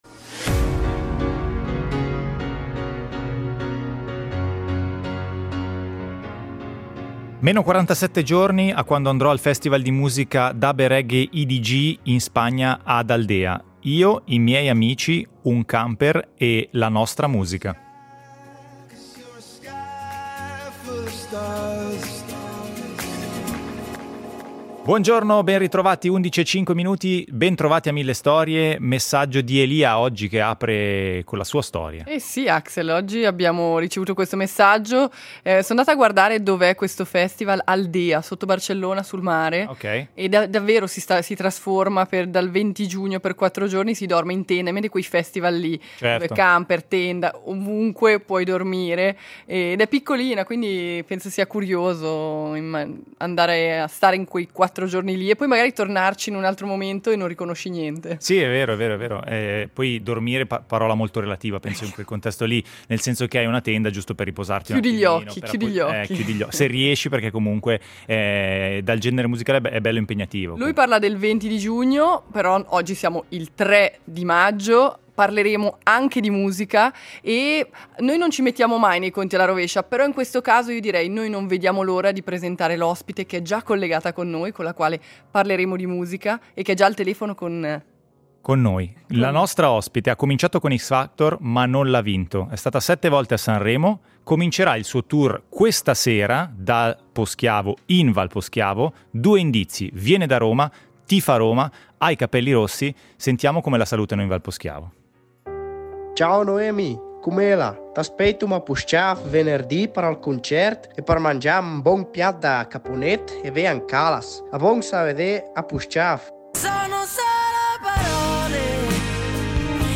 Noemi torna in Svizzera e torna nella Svizzera italiana, a Poschiavo da dove comincia il suo tour di concerti. Voce potentissima, romana e romanista, capelli rossi è passata a trovarci.